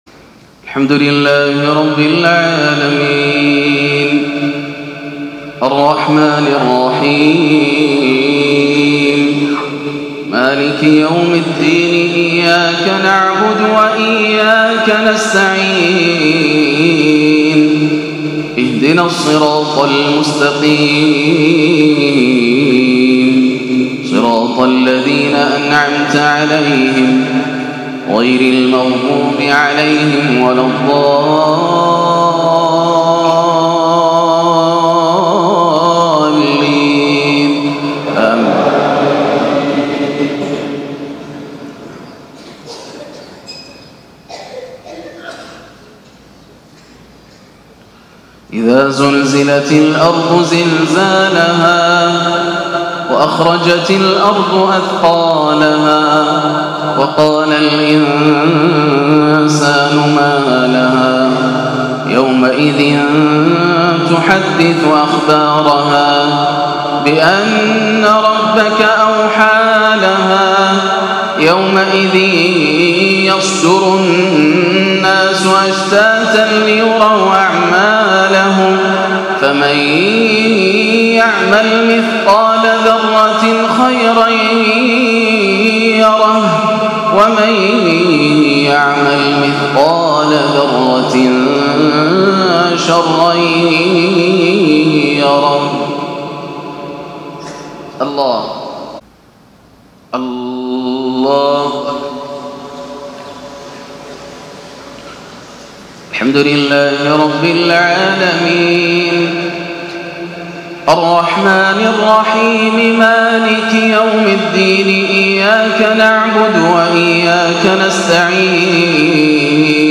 صلاة المغرب 3-3-1439هـ سورتي الزلزلة و التكاثر > عام 1439 > الفروض - تلاوات ياسر الدوسري